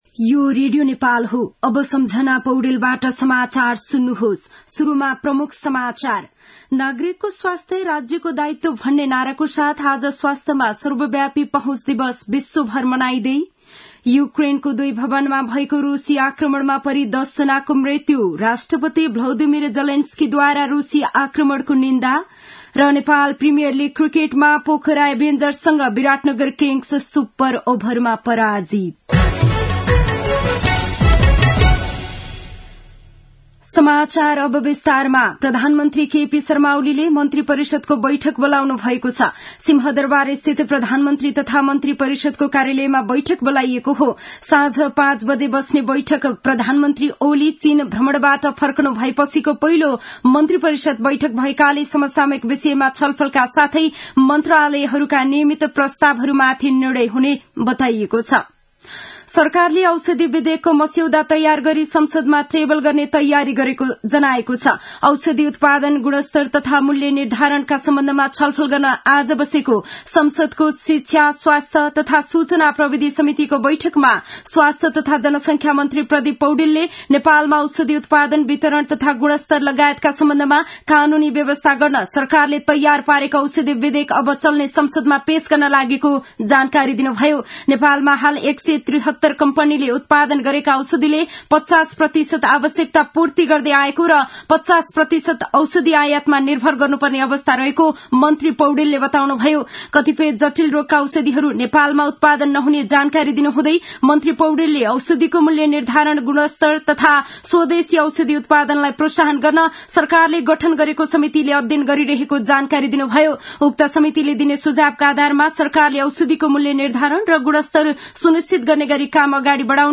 दिउँसो ३ बजेको नेपाली समाचार : २८ मंसिर , २०८१
3-pm-nepali-news-1-8.mp3